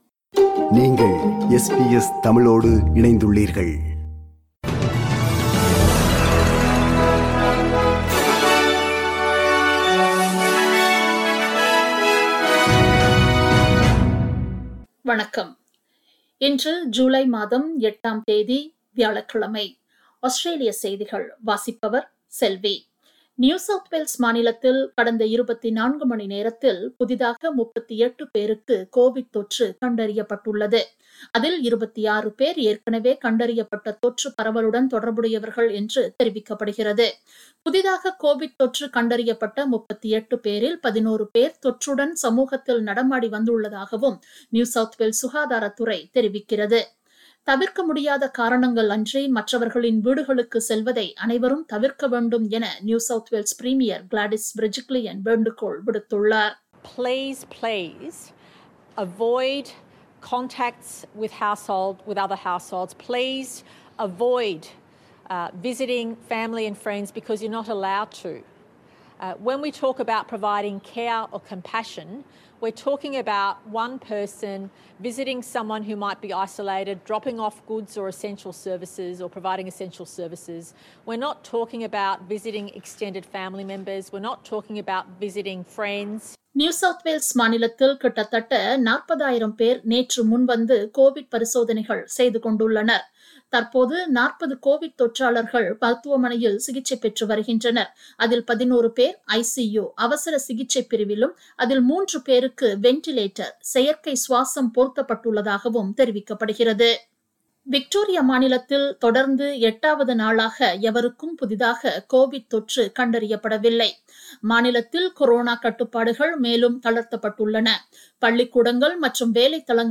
Australian news bulletin for Thursday 08 July 2021.